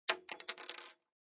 descargar sonido mp3 alfiler